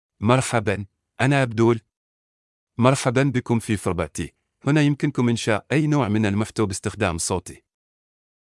Abdullah — Male Arabic (Oman) AI Voice | TTS, Voice Cloning & Video | Verbatik AI
MaleArabic (Oman)
Abdullah is a male AI voice for Arabic (Oman).
Voice sample
Listen to Abdullah's male Arabic voice.
Abdullah delivers clear pronunciation with authentic Oman Arabic intonation, making your content sound professionally produced.